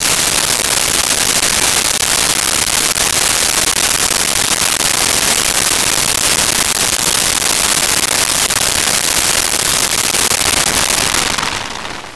Fireworks Spinner 02
Fireworks Spinner 02.wav